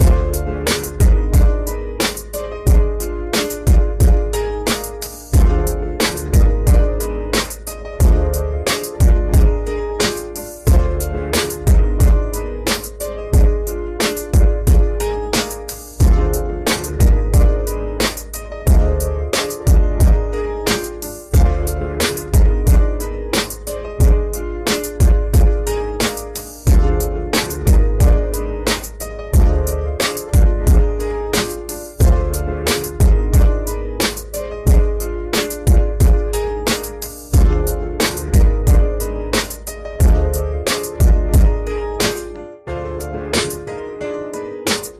Prompt :Hip hop boom bap beat, MPC 1000, 90 BPM, east coast new york, beat, snares, charley, piano melody